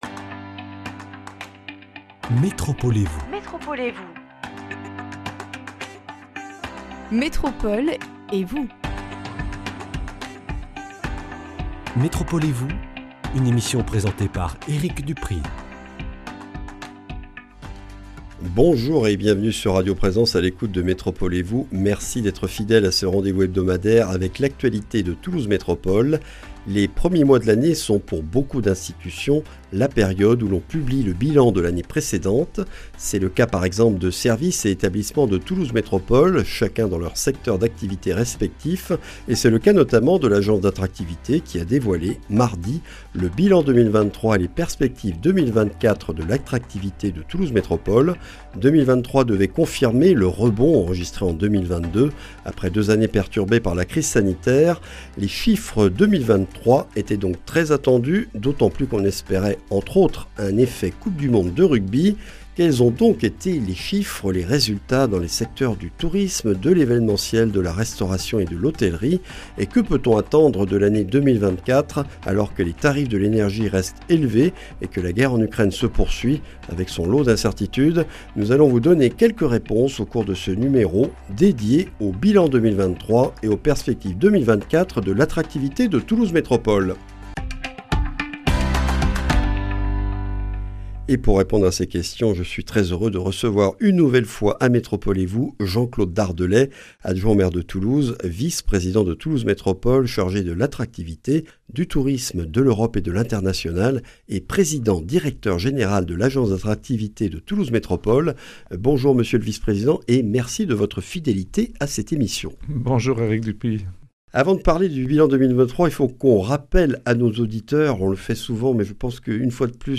Une émission avec Jean-Claude Dardelet, adjoint au maire de Toulouse, vice-président de Toulouse Métropole chargé de l’attractivité, du tourisme, de l’Europe et de l’international, PDG de l’Agence d’attractivité. Nous revenons avec lui sur le Bilan 2023 de l’attractivité de Toulouse Métropole et les perspectives et grands rendez-vous 2024 qu’il a récemment présentés au public.